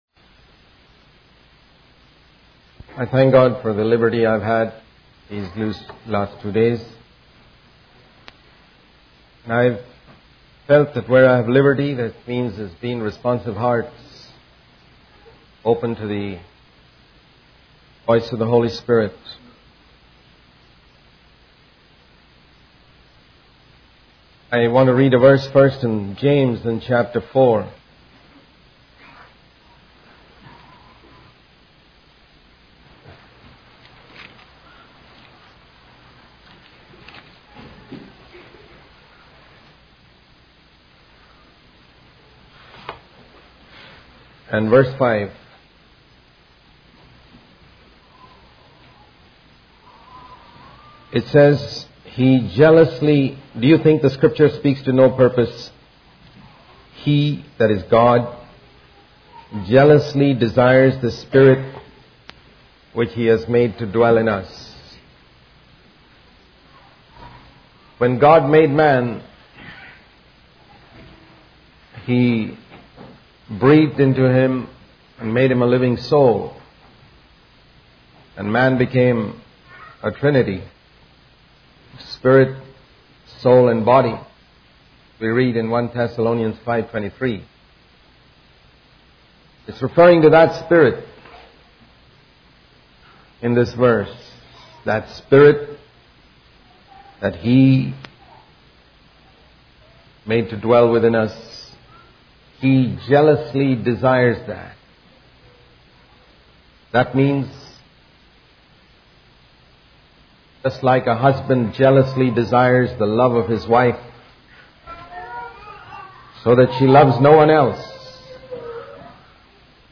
In this sermon, the speaker emphasizes the importance of God breaking us in order to use us effectively. He uses the example of Jacob being broken for twenty years in his father-in-law's house and Moses being broken in the wilderness for forty years.